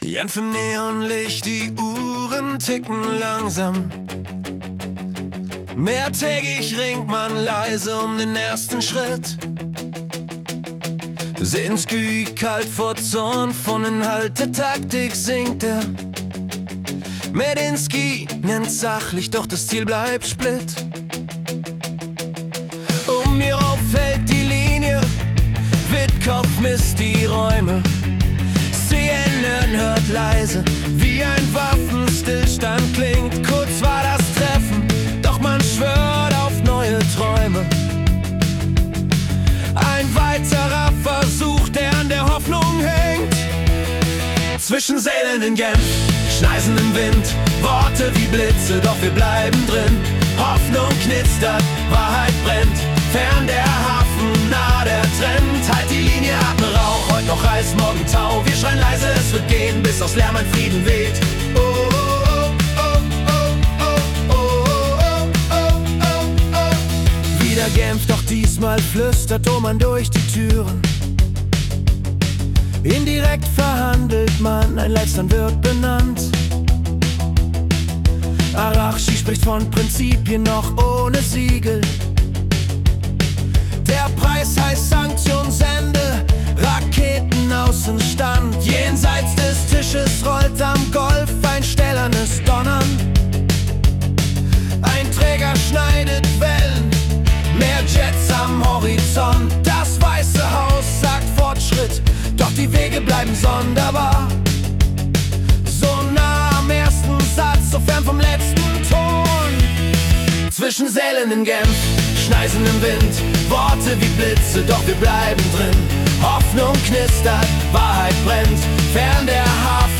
Februar 2026 als Rock-Song interpretiert.